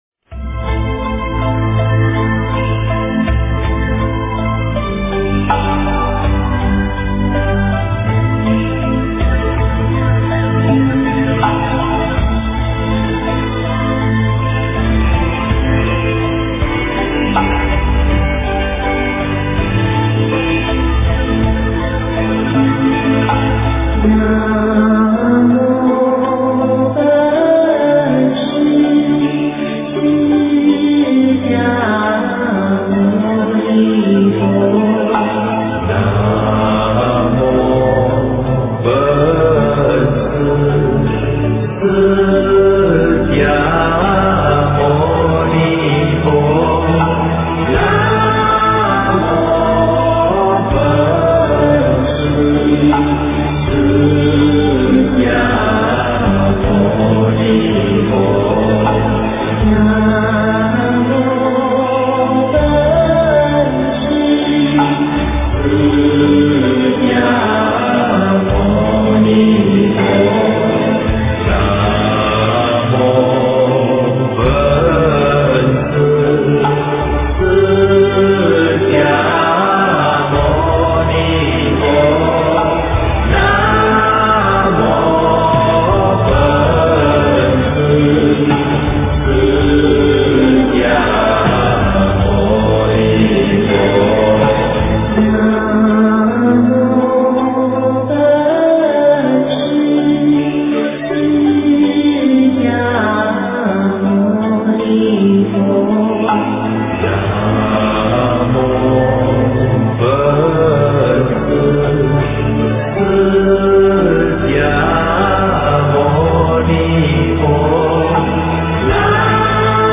经忏
佛音 经忏 佛教音乐 返回列表 上一篇： 普贤行愿品--普寿寺 下一篇： 大悲咒.念诵--普寿寺 相关文章 观世音菩萨普门品--陕西歌舞剧院民乐队 观世音菩萨普门品--陕西歌舞剧院民乐队...